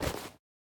Minecraft Version Minecraft Version snapshot Latest Release | Latest Snapshot snapshot / assets / minecraft / sounds / item / bundle / insert3.ogg Compare With Compare With Latest Release | Latest Snapshot